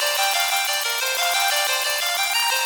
Index of /musicradar/shimmer-and-sparkle-samples/90bpm
SaS_Arp04_90-E.wav